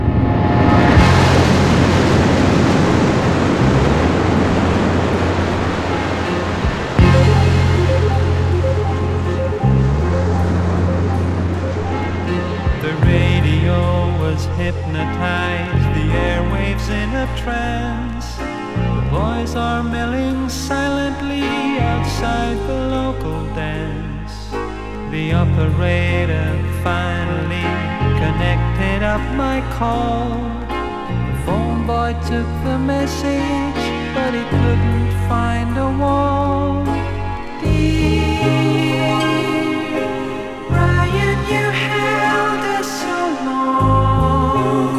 高密度なポップスを展開した宅録感満載の傑作。
Pop, Rock　Netherlands　12inchレコード　33rpm　Stereo